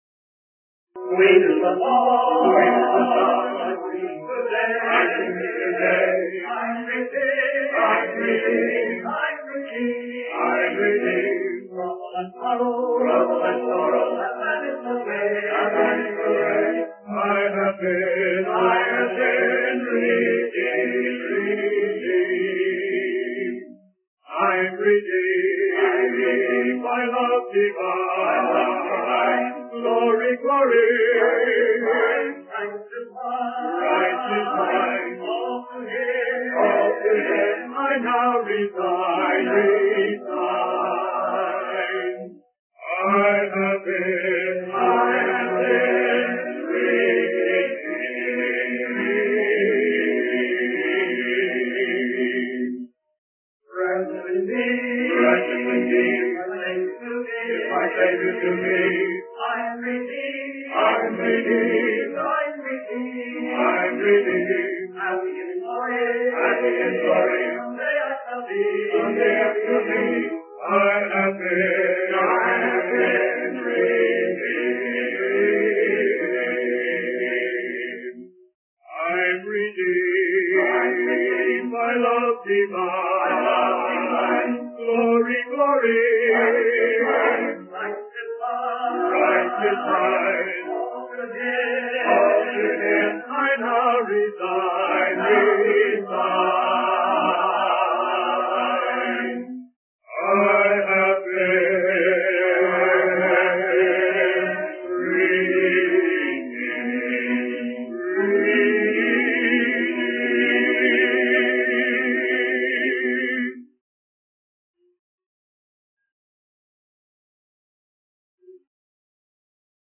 In this sermon, the preacher discusses the story of Samson from the Bible. He emphasizes the importance of being open to the lessons taught by the Spirit of God and allowing them to impact our lives.